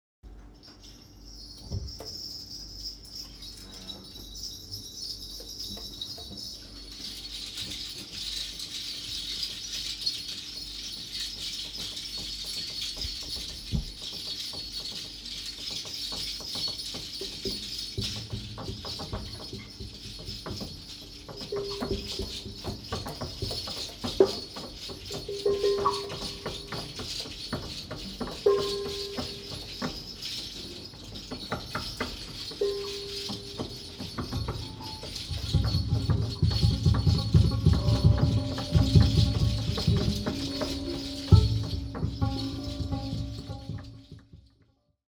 Recorded on Oct. 9.2025 at Jazz Bar Nardis